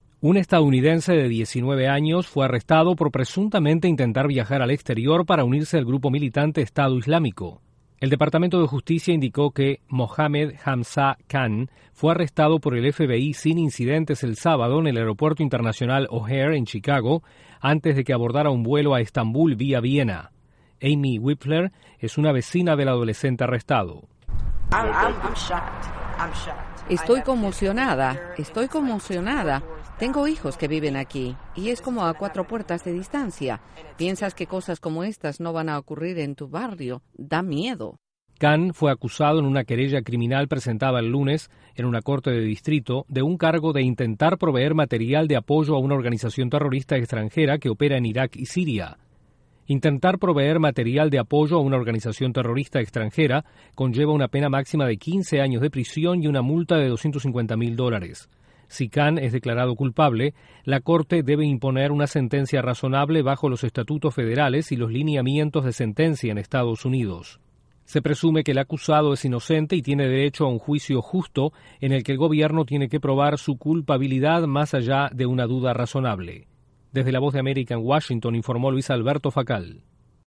Un adolescente estadounidense fue arrestado al intentar viajar al exterior para unirse al autodenominado Estado Islámico. Desde la Voz de América en Washington informa